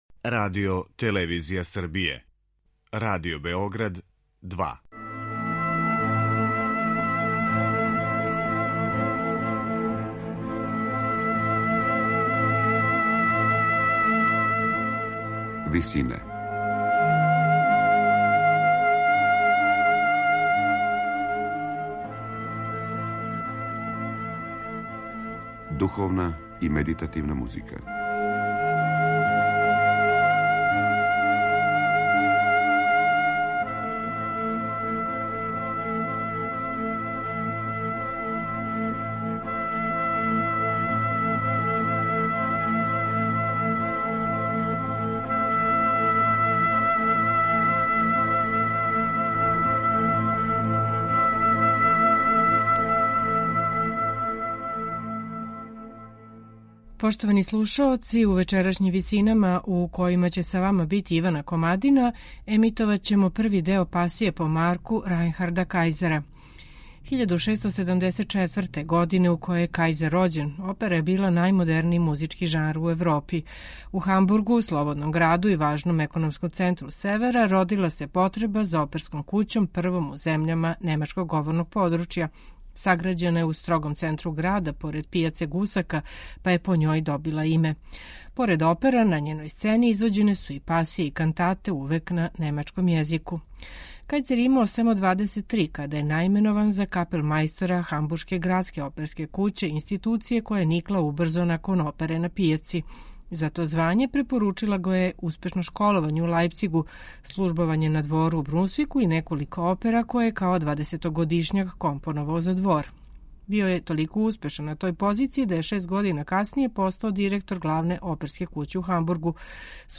тенор
вокални ансамбл
инструментални ансамбл